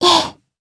Lewsia_A-Vox_Happy1_jp.wav